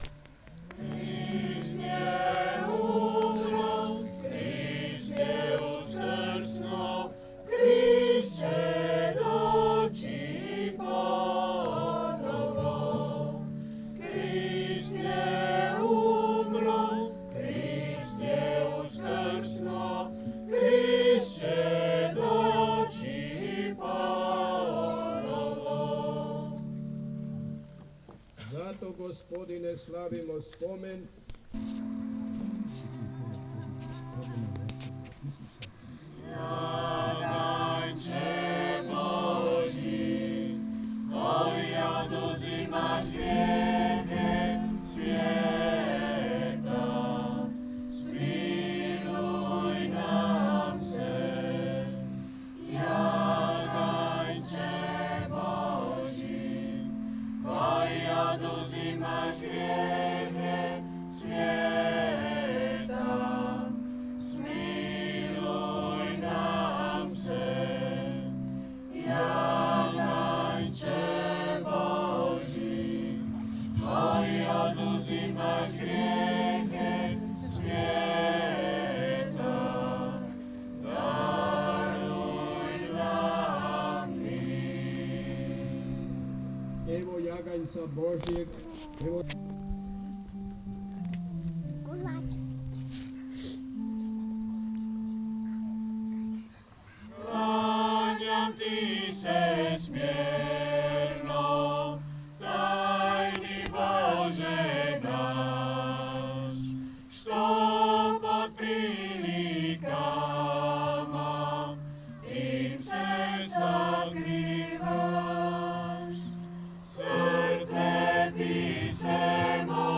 sound recording from a mass at Sveta Magdalena.